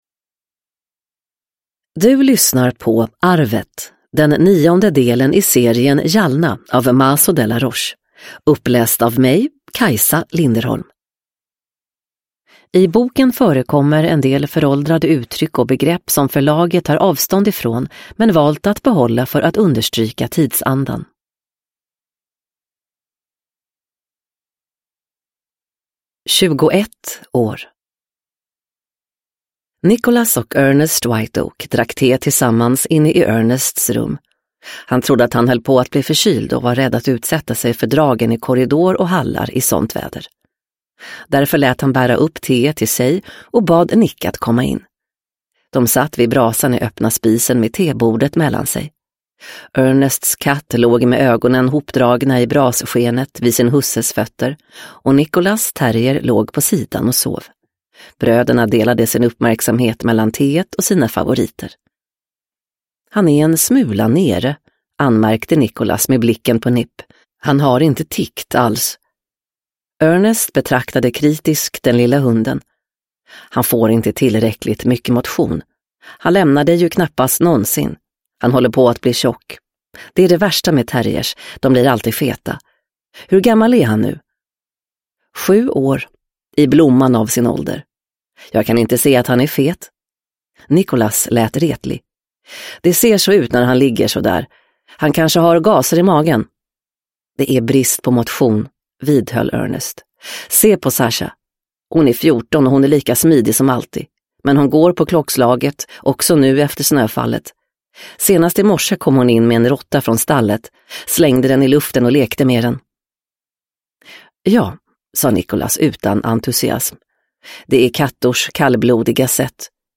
Arvet – Jalna 9 – Ljudbok – Laddas ner